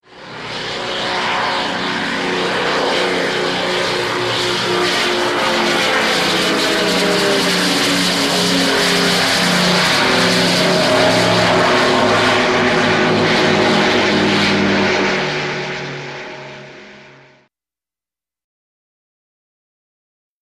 WW2 Fighters|P-38|Single
Airplane P-38 Pass By Slow Directly Overhead